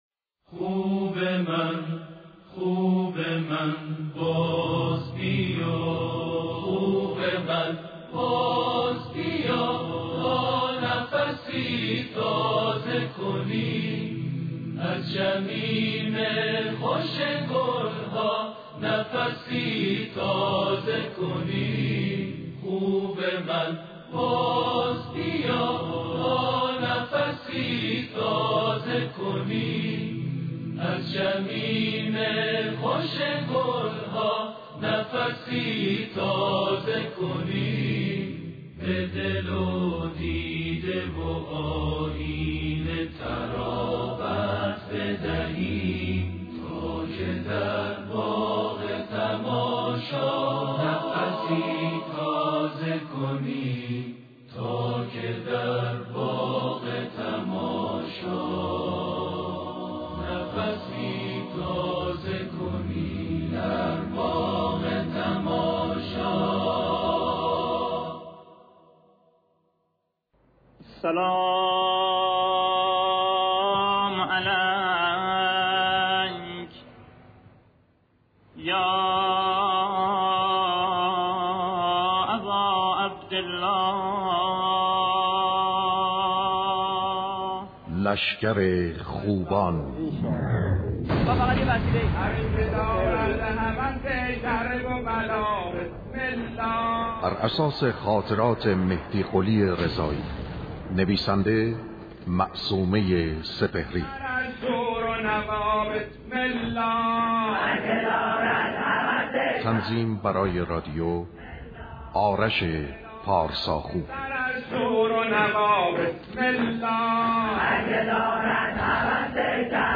نمایش رادیویی لشگر خوبان